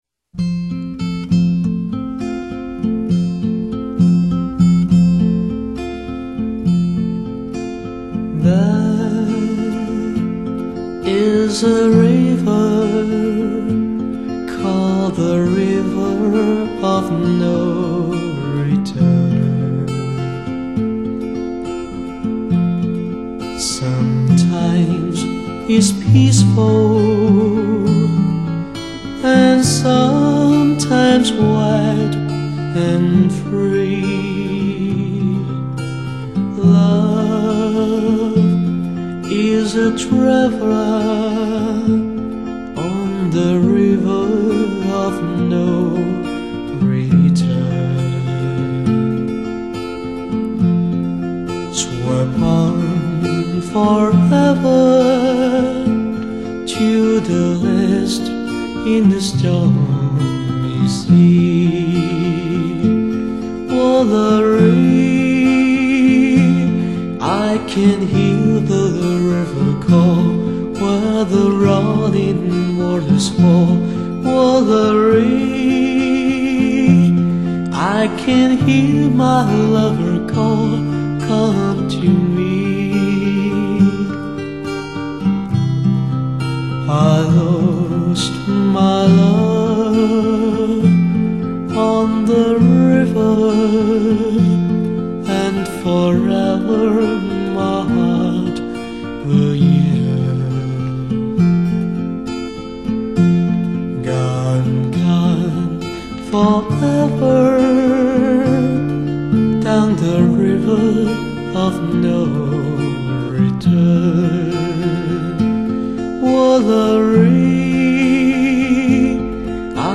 语    种：纯音乐
低吟浅唱，有如天籁，直触人心，荡埃涤尘。木吉他的朴素和弦，民谣歌手的真情演绎，帮我们寻回人类的童真，生活的安宁。